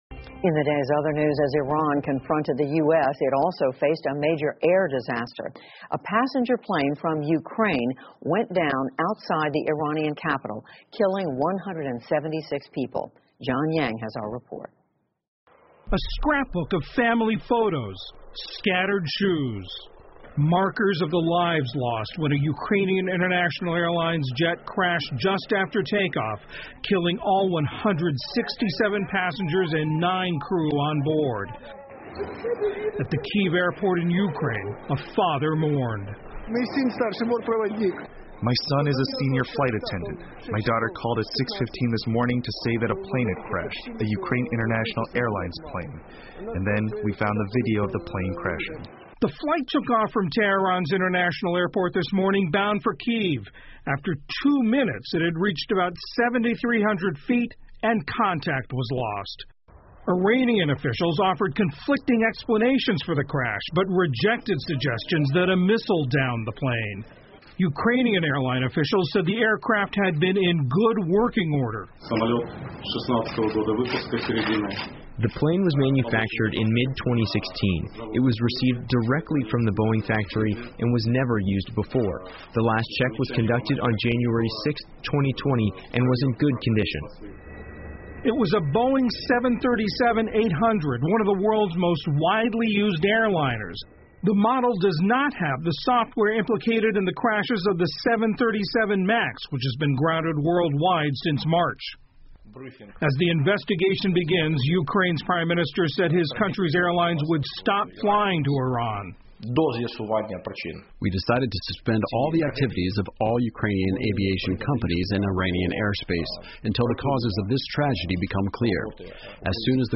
PBS高端访谈: 听力文件下载—在线英语听力室